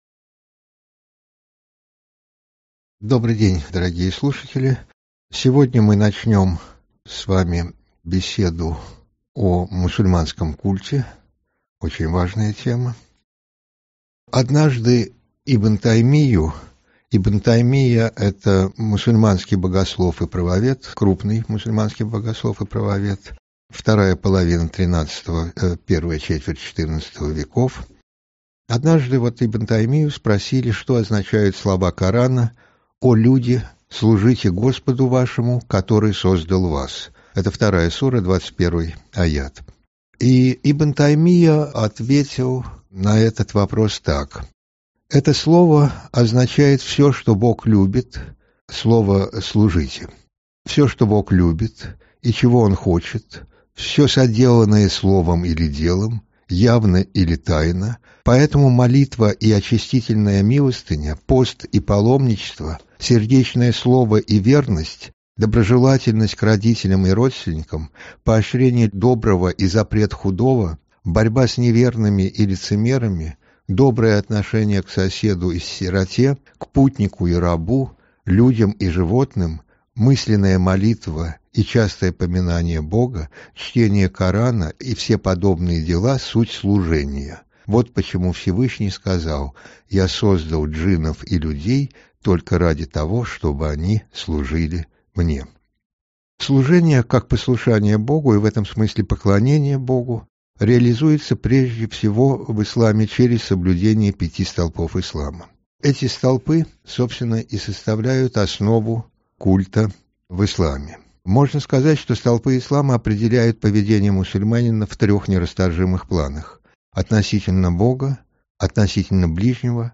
Аудиокнига Пять столпов ислама: шахада, молитва, закят | Библиотека аудиокниг